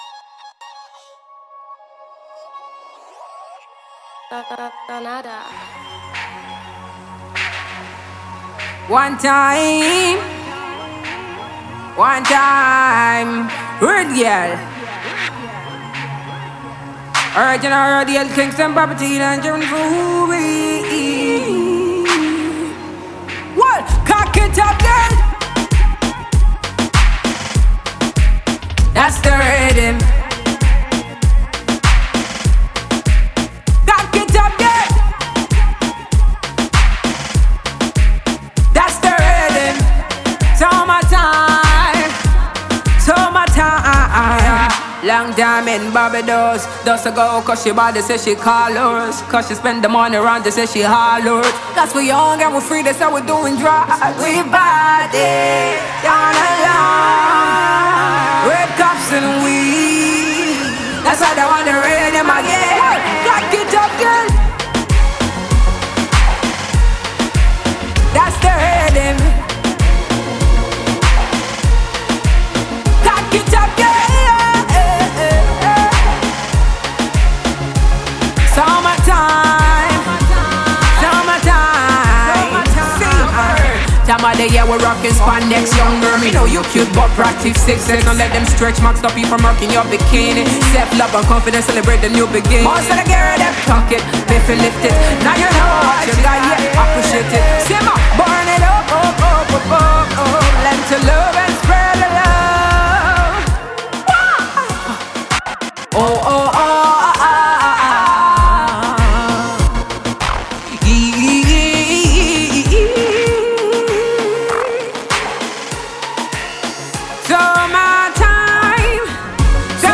Nigeria’s female dance hall singer
a 3-way style – ragga, rap & melodious singing.